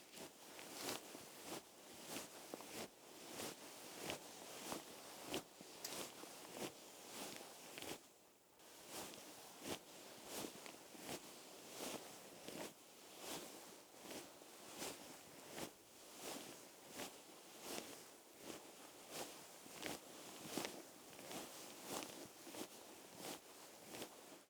household
Cloth Movements Slow